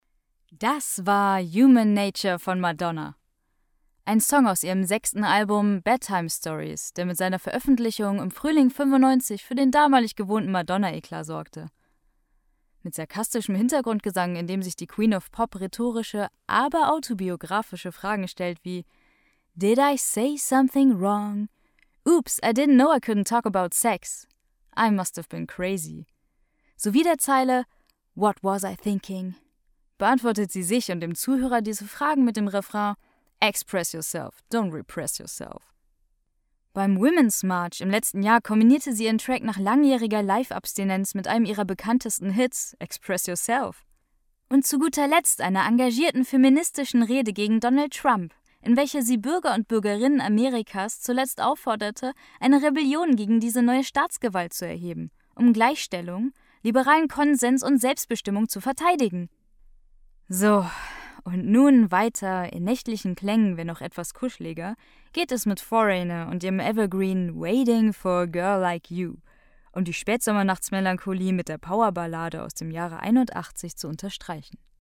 sehr variabel, markant, dunkel, sonor, souverän
Jung (18-30)
Monolog - melancholisch
Off, Tale (Erzählung), Audio Drama (Hörspiel)